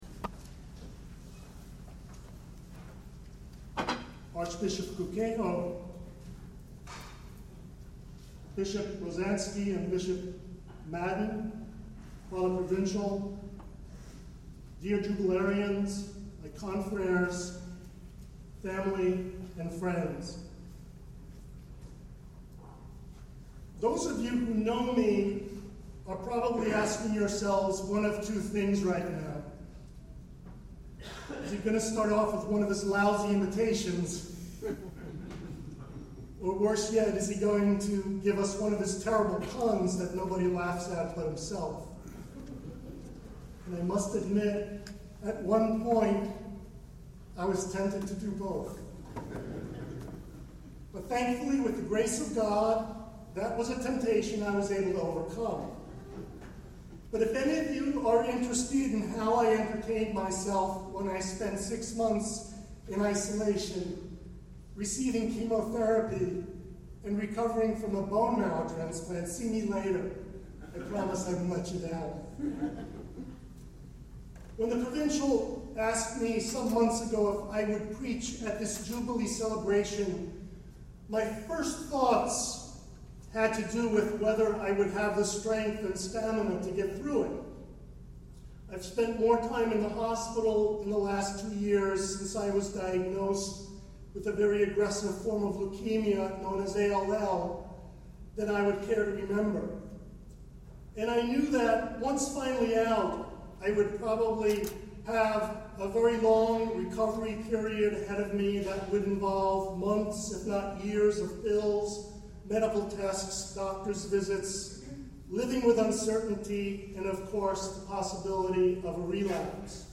Redemptorist Jubilee Celebration 2012 -- Homily